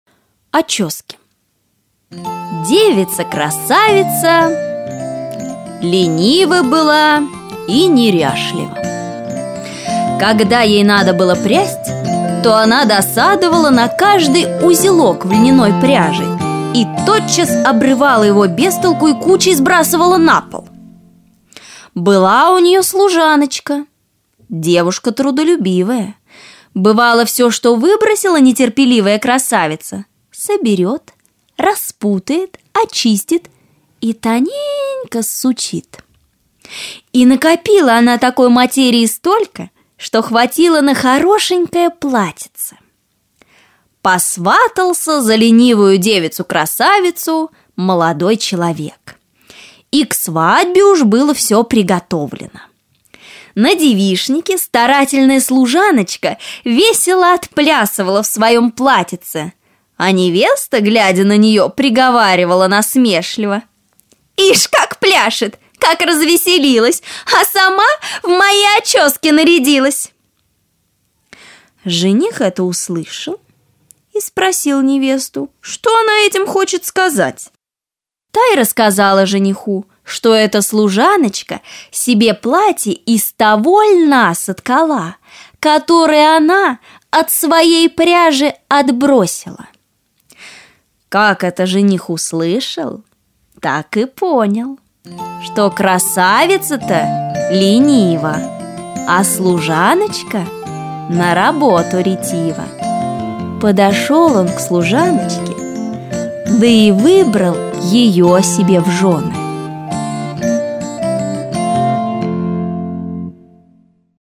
Аудиосказка Очески